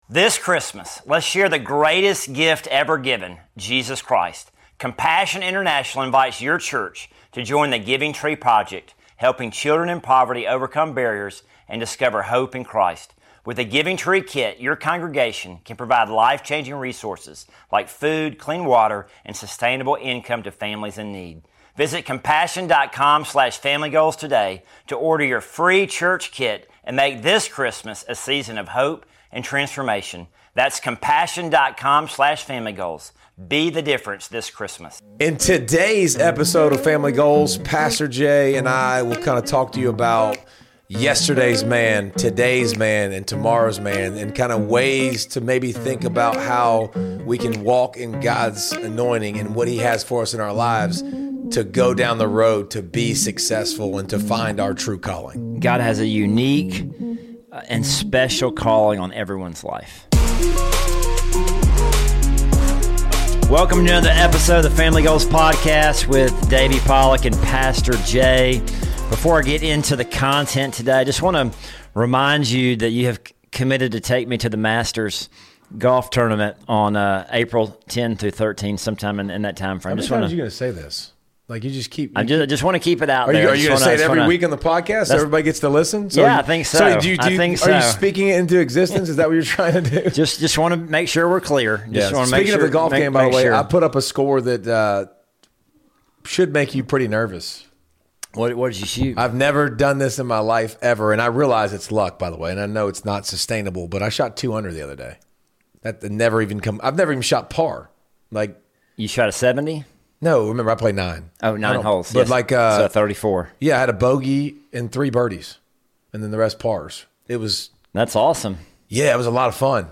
have a weekly conversation about God